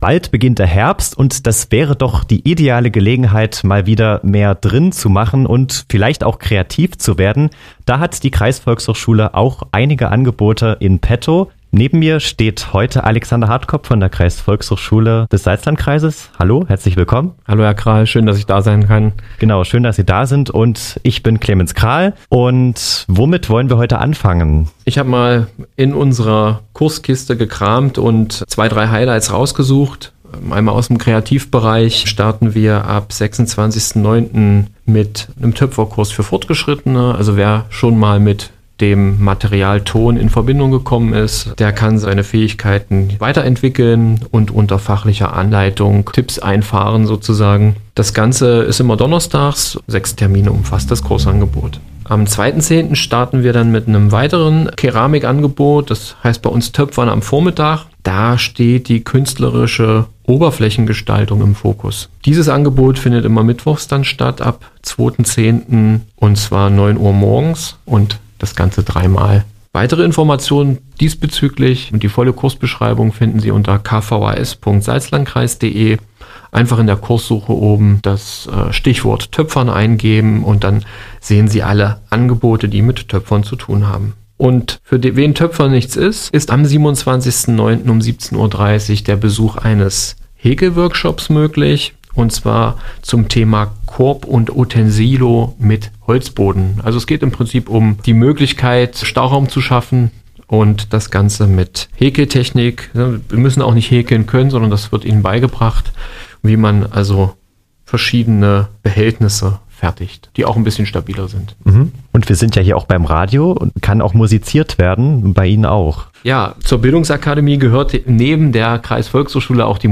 Hörbeitrag vom 12. September 2024